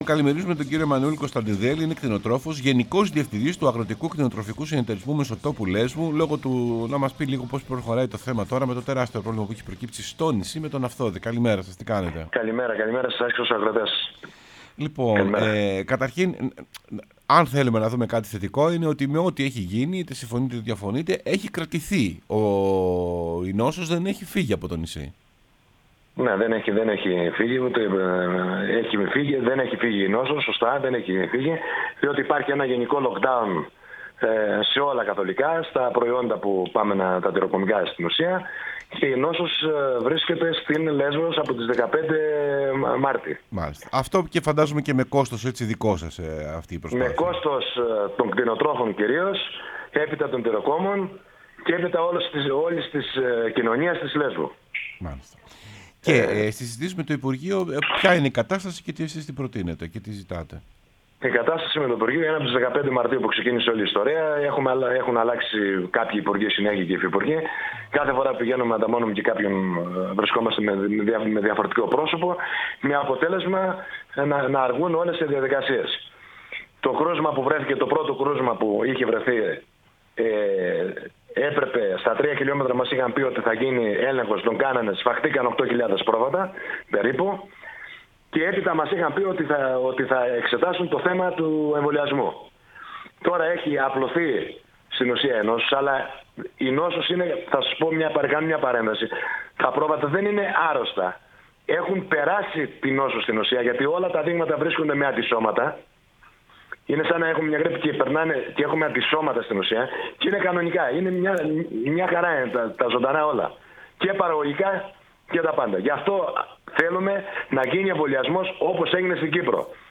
μίλησε στην εκπομπή «Σεμνά και Ταπεινά»